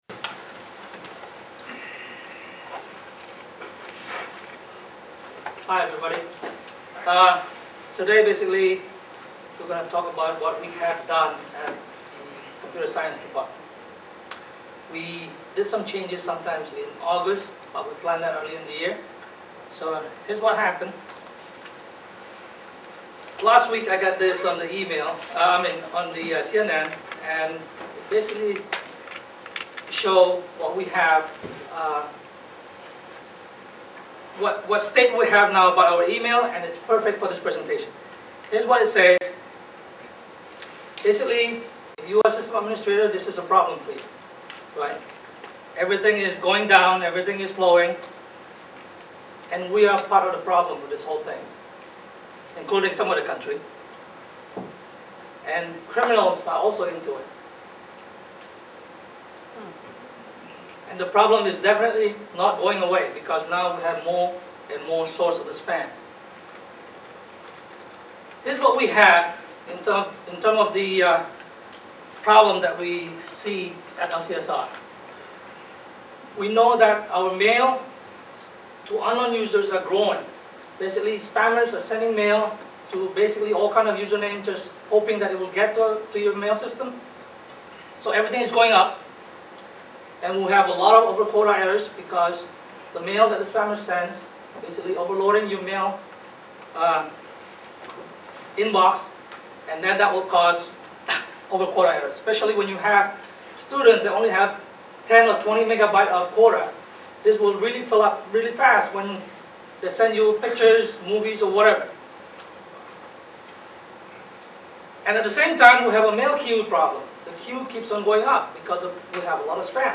PDF of slides MP3 of the audio of the talk Quicktime of talk synchronized with slide changes Podcast-sized of talk with slides PowerPoint slides